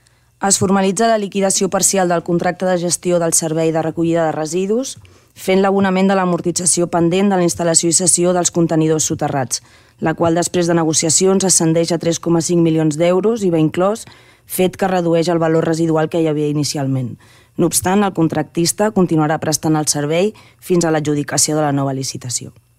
Ho explicava la regidora d’hisenda, Jenifer Jansa.